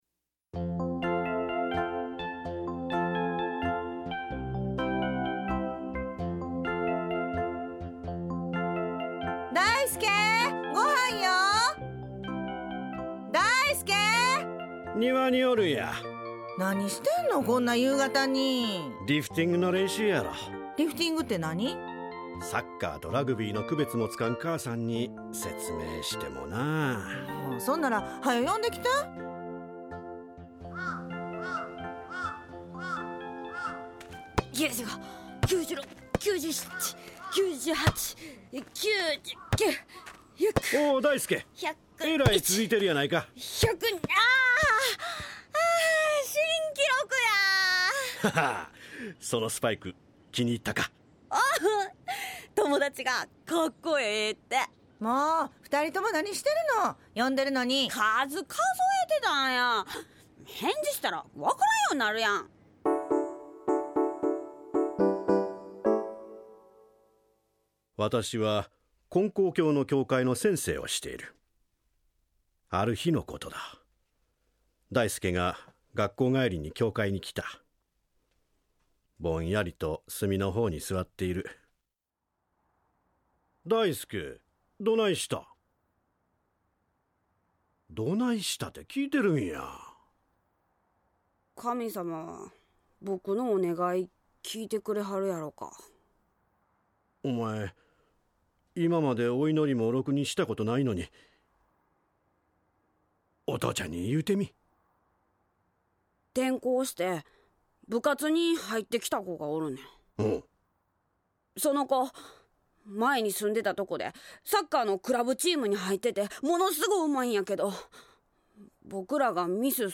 ●ラジオドラマ「ようお参りです」
大介だいすけ（子供）
・父（大介の父・教会の先生）
・母（大介の母）